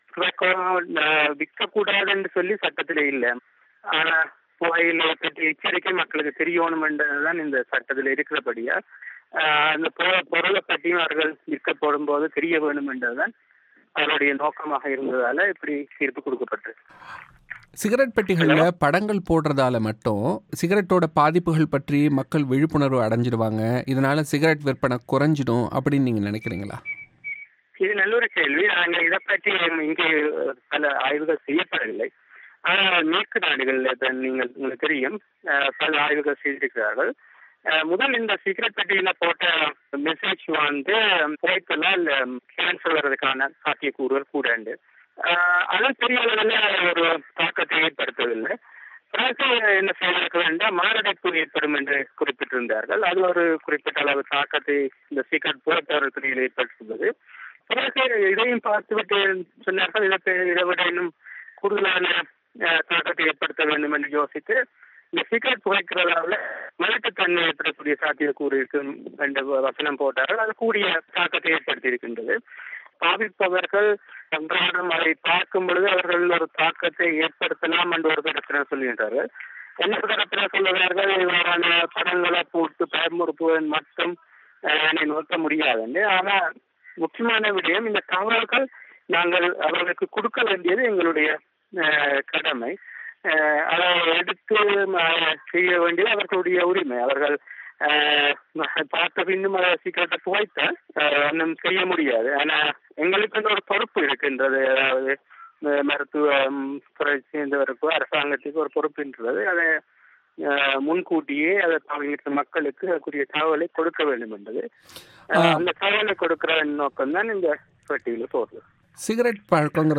செவ்வி.